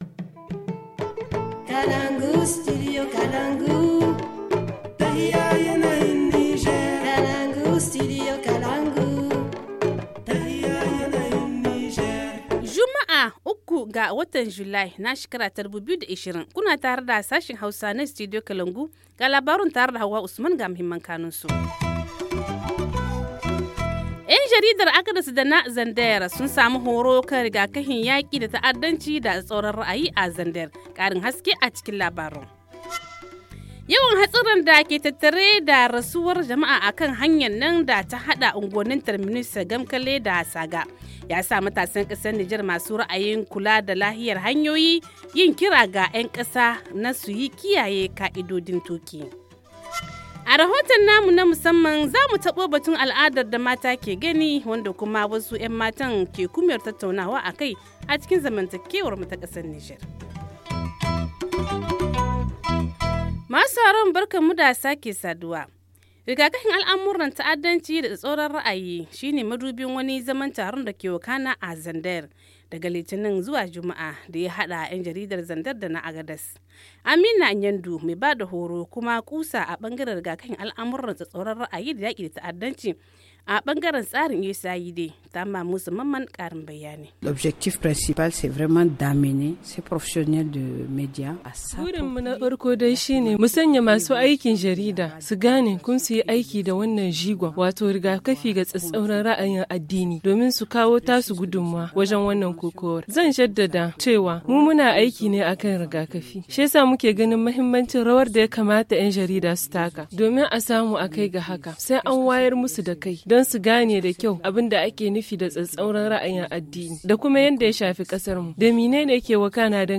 Le journal du 03 juillet 2020 - Studio Kalangou - Au rythme du Niger